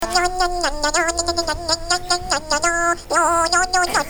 Play Annoying Sound - SoundBoardGuy
annoying-sound.mp3